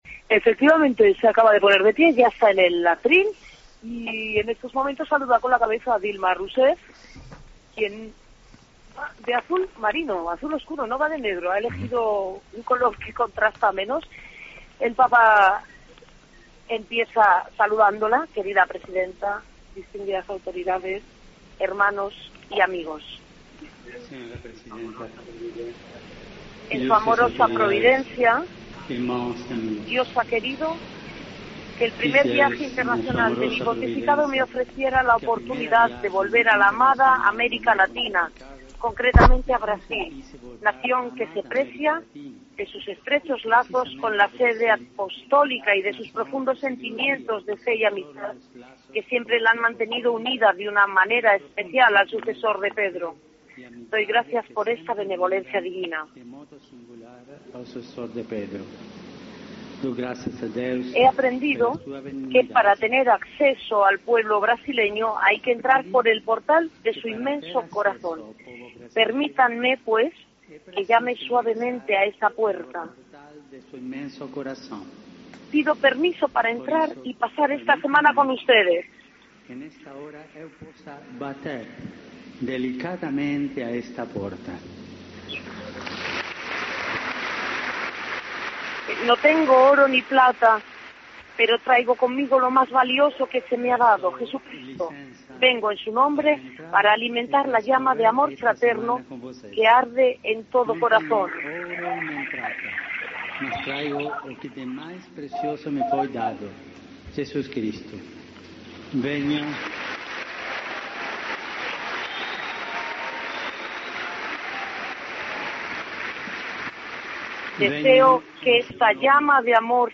Discurso de Francisco: 'No tengo oro, ni plata. Traigo conmigo lo más valioso que se me ha dado, Jesucristo'